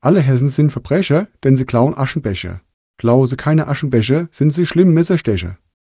Die Stimme kann sogar Hessisch
Eine Variante spricht sogar mit authentischem hessischem Dialekt.